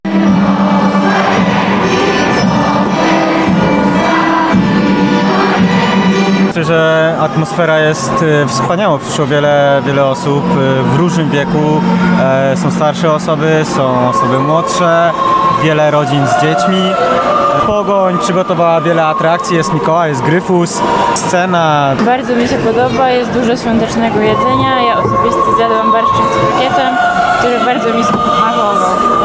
Wczoraj na stadionie im. Floriana Krygiera odbyło się kolędowanie z Portowcami. Atrakcji było sporo – zdjęcia z Mikołajem i Gryfusem, piłkarze Pogoni rzucający piłki do publiczności, występ chóru czy wspólne śpiewanie kolęd.